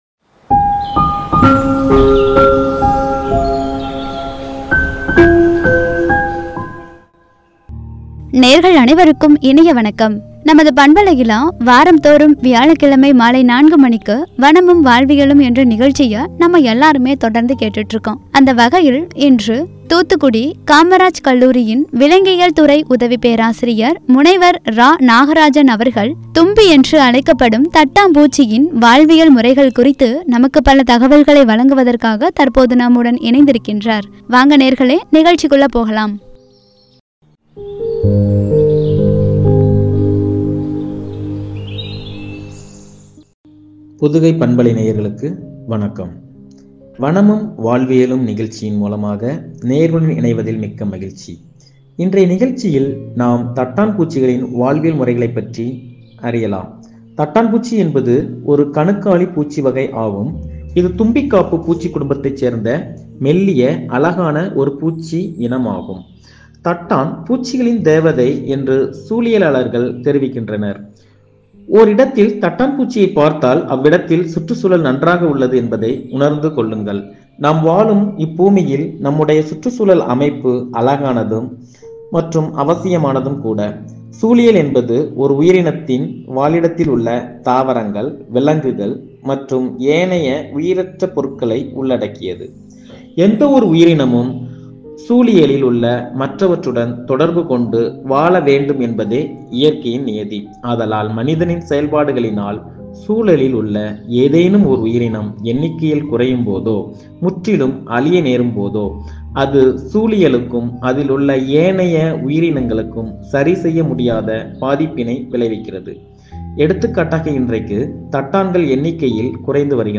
தும்பியின் வாழ்வியல் முறைகள்” வனமும் வாழ்வியலும் (பகுதி – 92) என்ற தலைப்பில் வழங்கிய உரை.